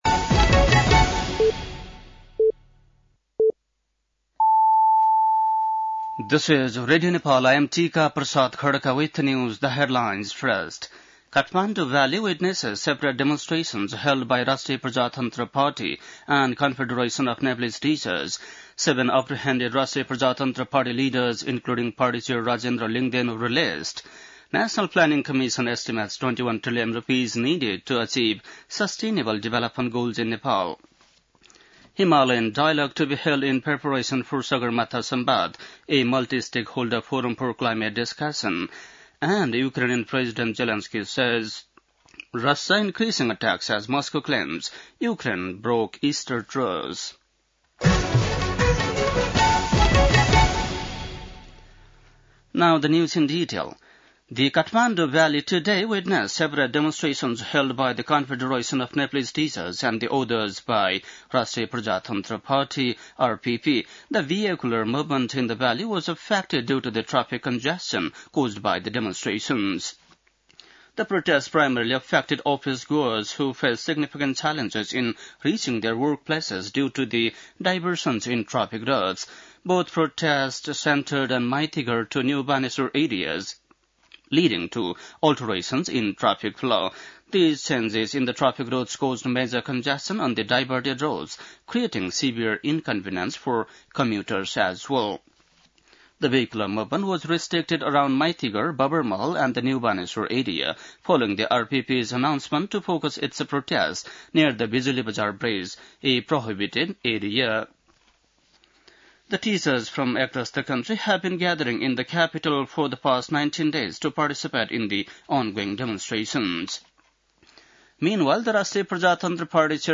बेलुकी ८ बजेको अङ्ग्रेजी समाचार : ७ वैशाख , २०८२
8-pm-english-news1-7.mp3